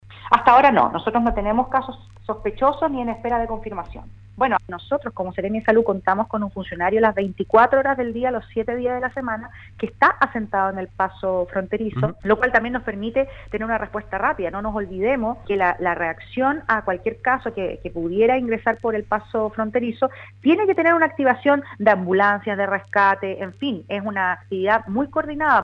En conversación con Radio Sago, la seremi de Salud de Los Lagos, Scarlett Molt, descartó que en la región existan casos sospechosos de coronavirus.